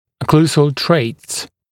[ə’kluːzəl treɪts] [-səl][э’клу:зэл трэйтс] [-трэйз]окклюзионные особенности, характерные черты окклюзии